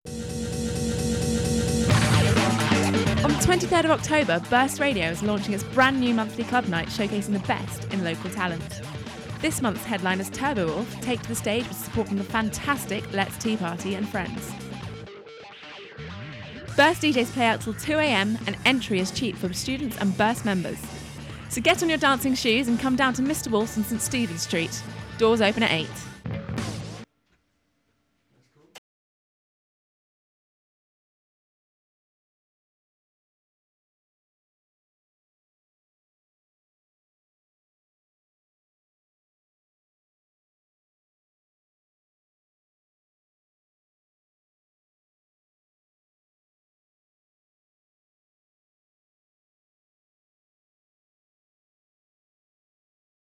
Club Night Launch Party Ad